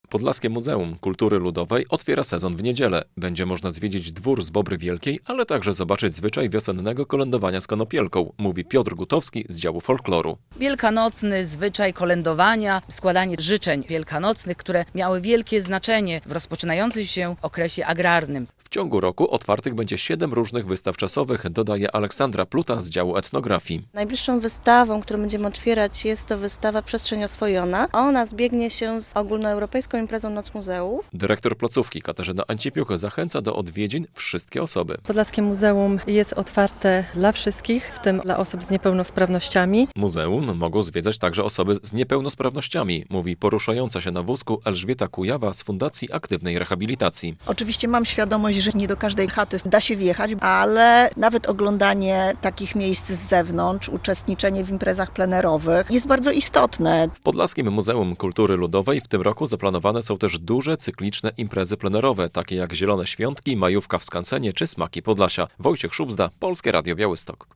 Podlaskie Muzeum Kultury Ludowej przygotowuje się do uroczystego rozpoczęcia sezonu - relacja